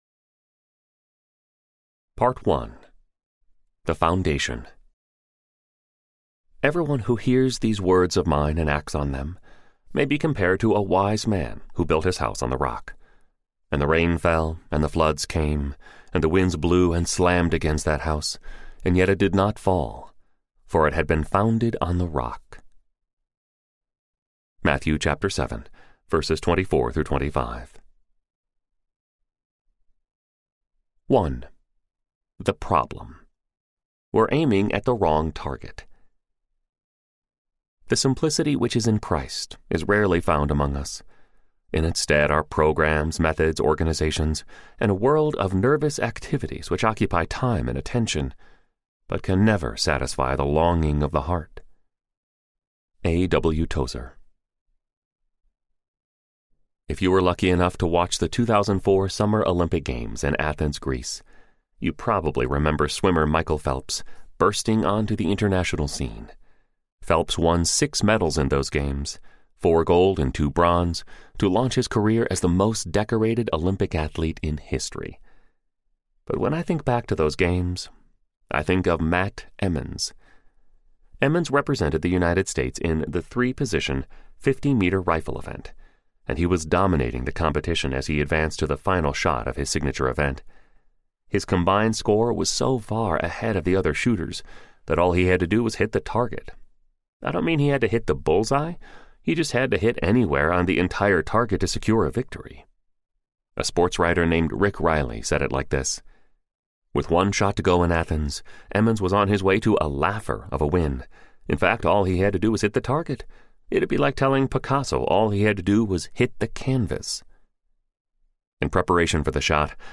Unburdened Audiobook
Narrator
5.5 Hrs. – Unabridged